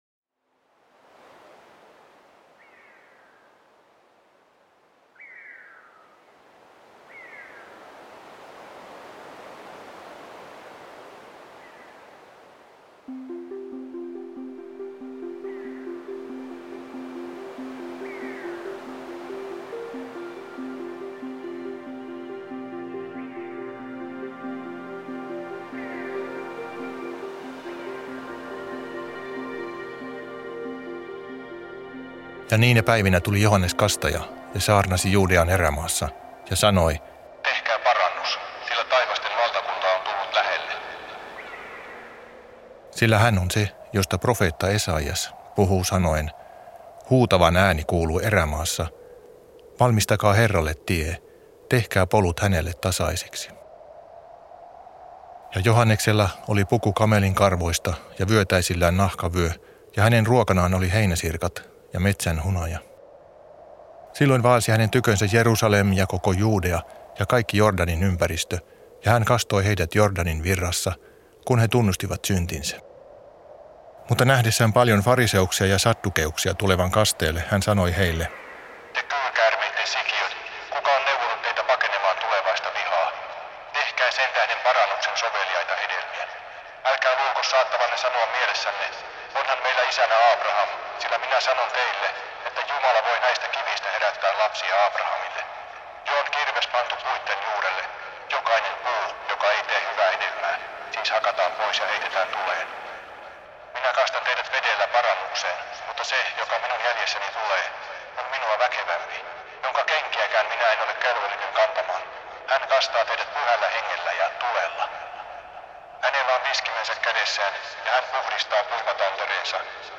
1. Moos. 22:1-18 Jumala koettelee Aabrahamia Raamatunlukua. 1. Mooseksen kirja luku 22, jakeet 1-18.
huilu.